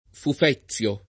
[ fuf $ZZL o ]